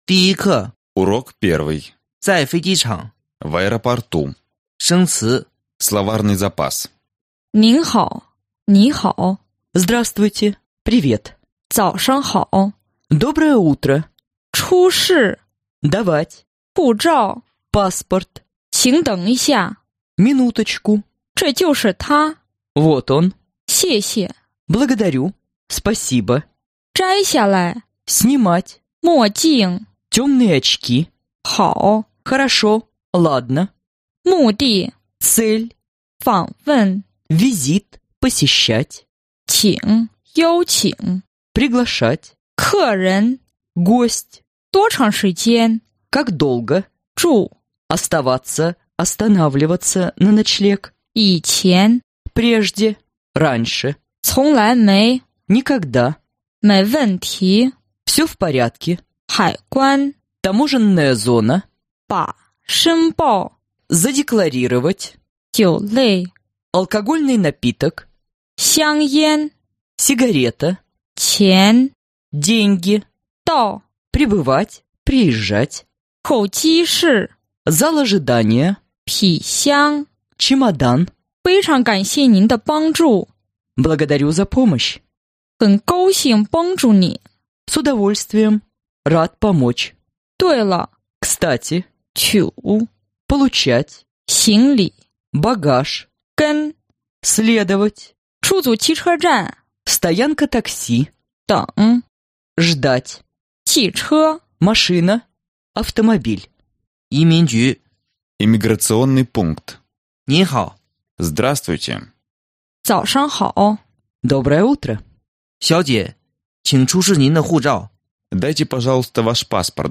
Аудиокнига Китайский язык за 2 недели | Библиотека аудиокниг
Aудиокнига Китайский язык за 2 недели Автор Коллектив авторов Читает аудиокнигу Профессиональные дикторы.